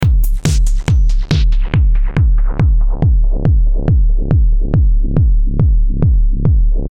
Drum Loops / Loops 19 Jan, 2026 High-Energy Breakbeat Drum Loop – 140 BPM Modern Jungle Rhythm Read more & Download...
High-energy-breakbeat-drum-loop-140-bpm-modern-jungle-rhythm.mp3